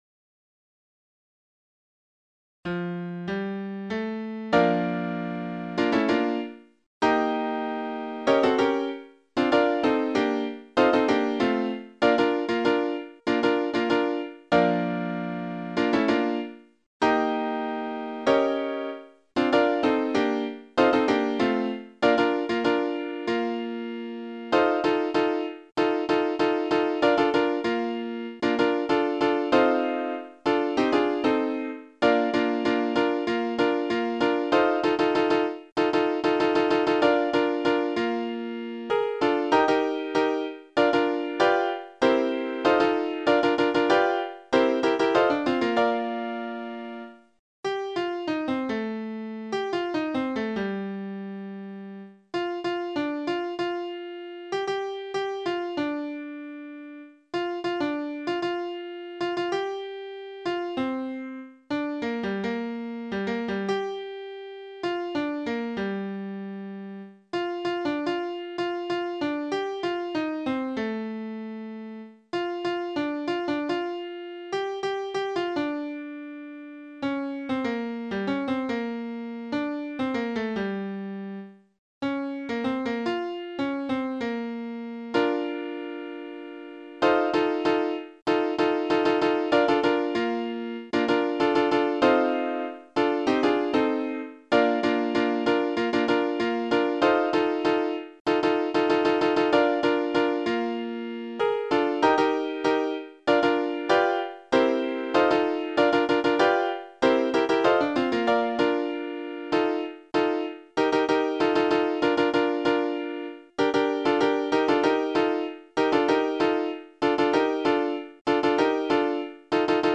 コーラスのオーディオファイル
３声